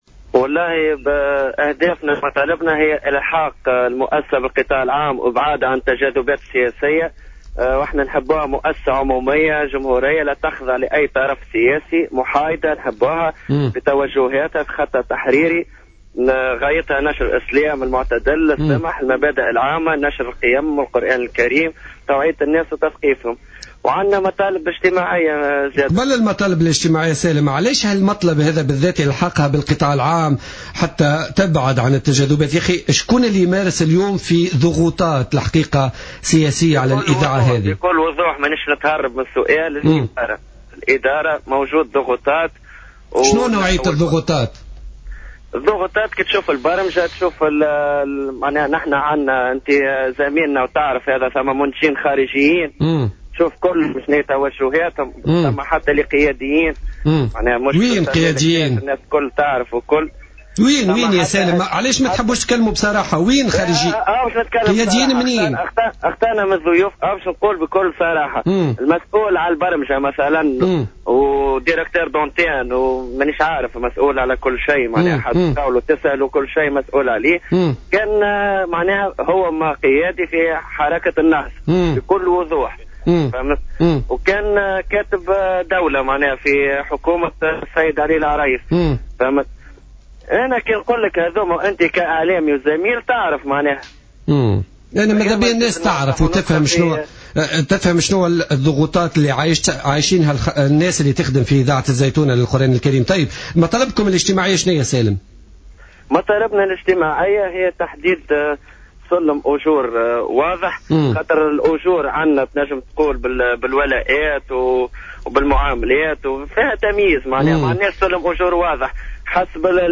في اتصال هاتفي خلال برنامج بوليتيكا